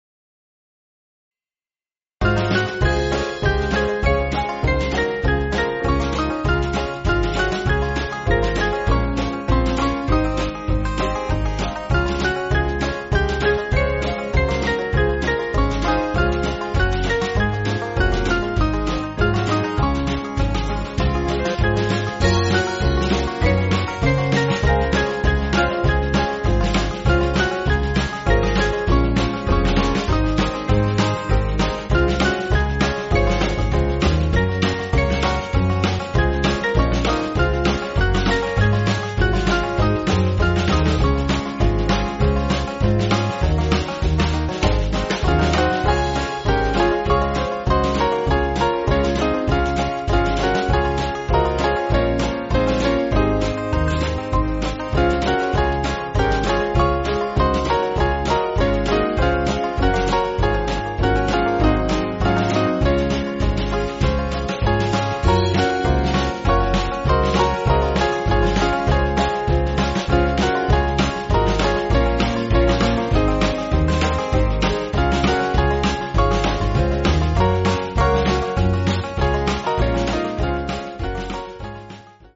Small Band
(CM)   4/Db